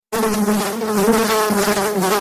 Рингтоны » звуки животных » Жужжание пчёл